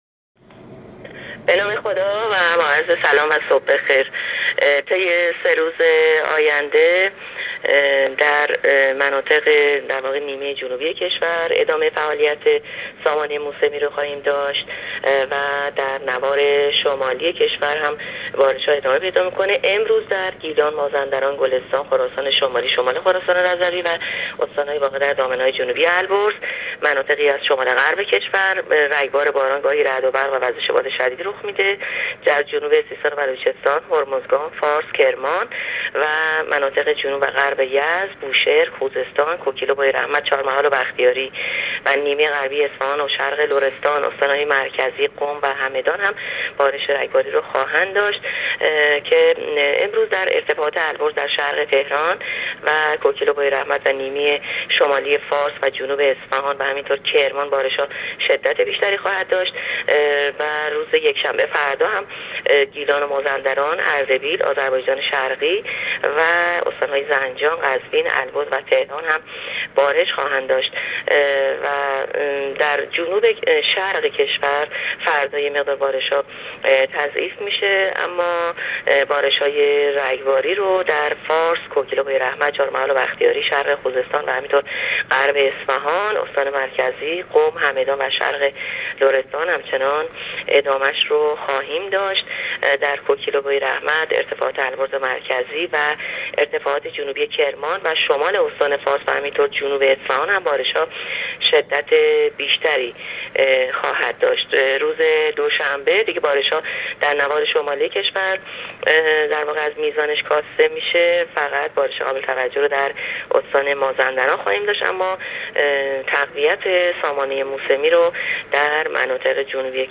گزارش رادیو اینترنتی پایگاه خبری از آخرین وضعیت آب‌وهوای هشتم مرداد ماه؛